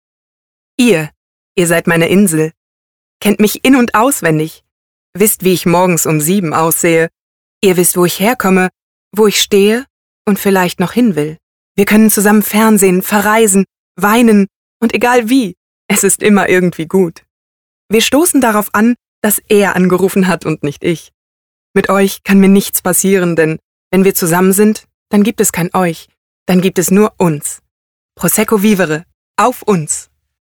Deutscher Sprecher, Off-Sprecher, mittlere bis tiefe Stimme, Hörspiel, Hörbuch, Voice over, Audiodeskription (Little Dream Entertainment, ARD, arte)
norddeutsch
Sprechprobe: Werbung (Muttersprache):